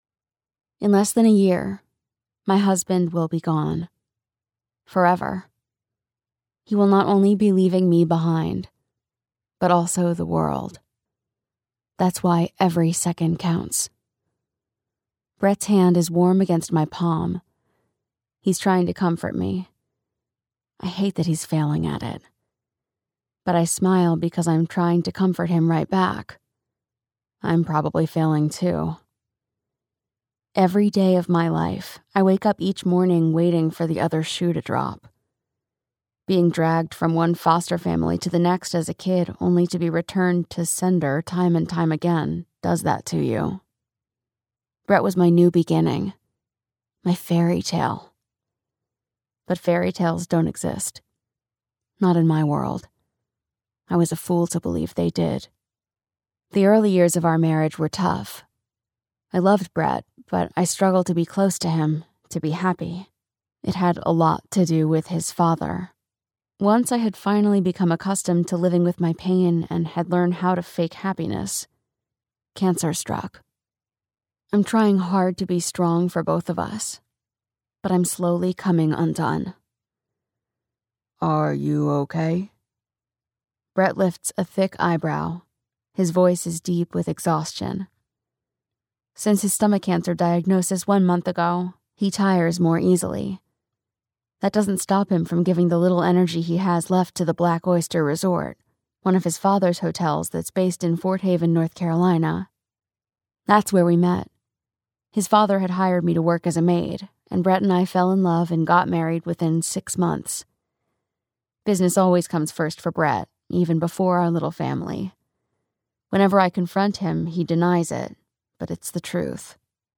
The Widow's Cabin - Vibrance Press Audiobooks - Vibrance Press Audiobooks